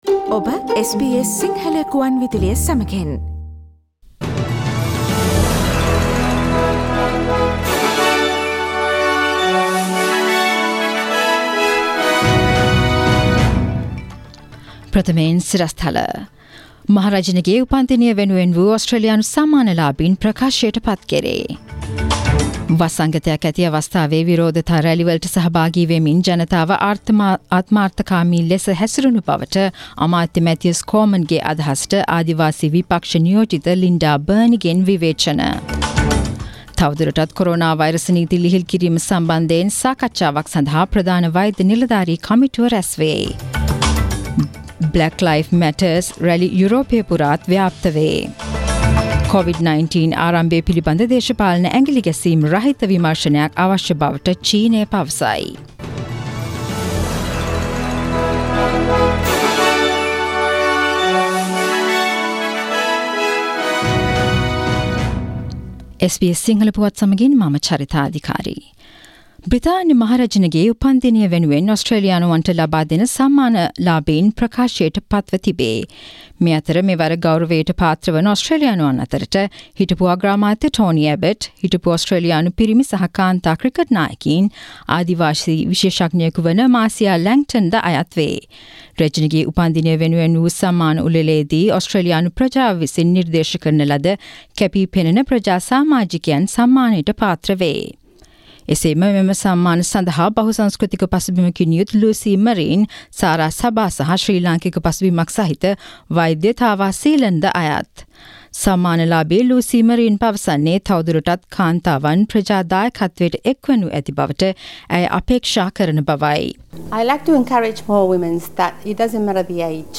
Daily News bulletin of SBS Sinhala Service: Monday 08th June 2020
Today’s news bulletin of SBS Sinhala Radio – Monday the 8th of June 2020.